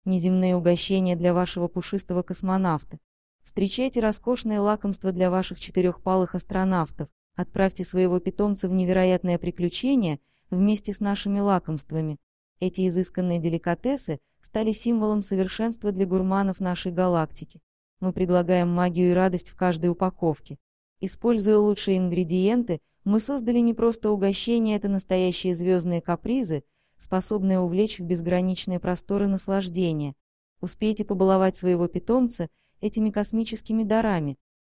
Озвучка рекламного текста